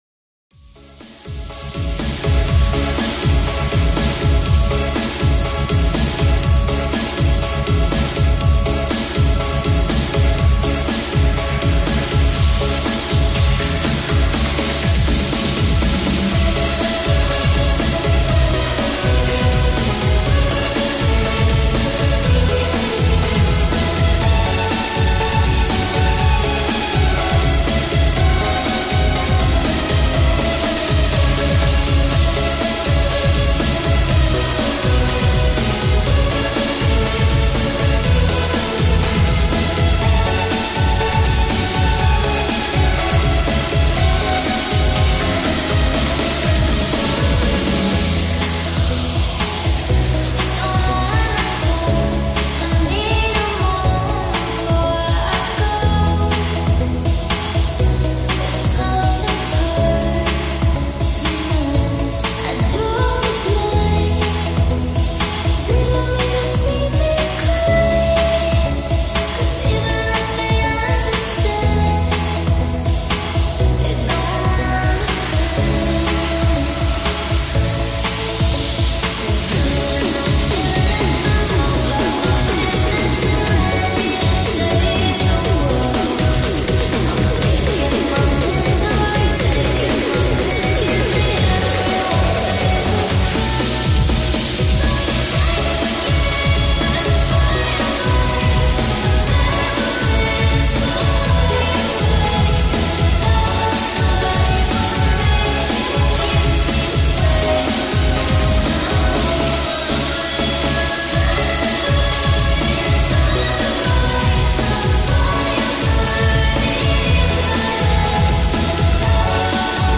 BREAKBEAT REMIX FULL BEAT